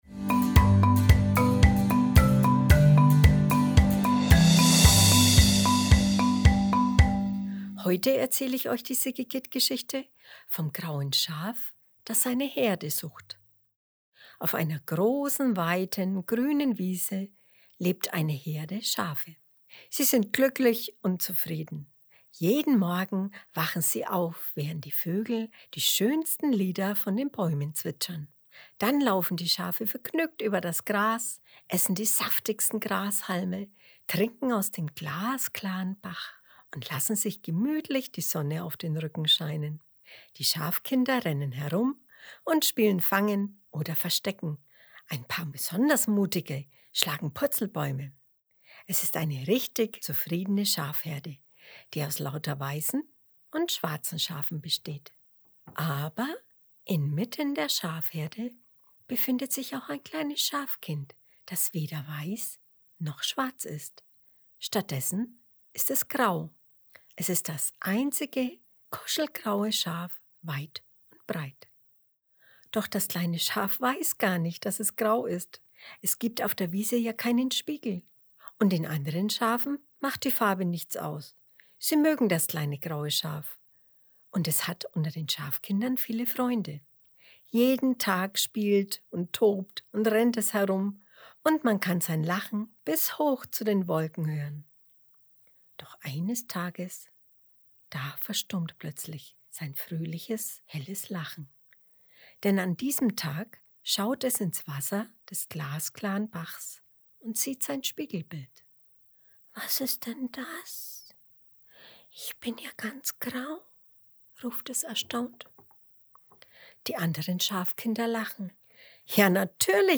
April 2022 Kinderblog Vorlesegeschichten Auf einer Wiese lebt eine Herde Schafe, die aus weißen und schwarzen Schafen besteht.